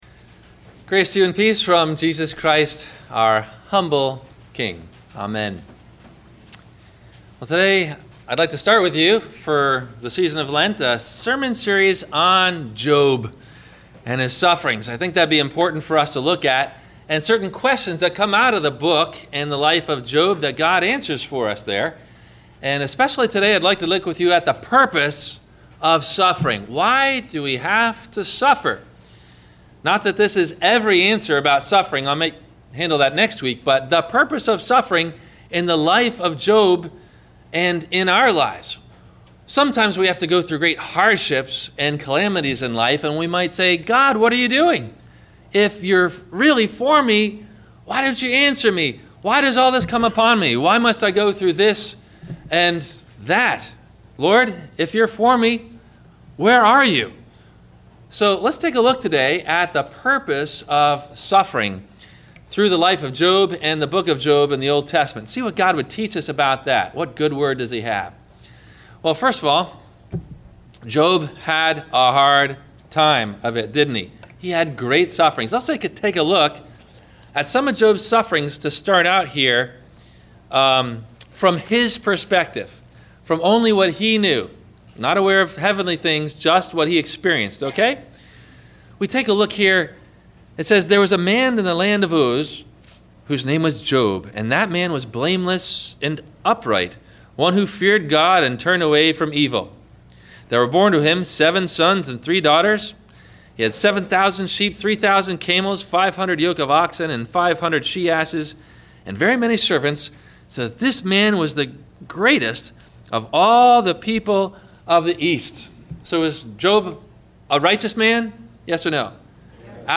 Why Do We Have To Suffer? – Sermon – February 14 2016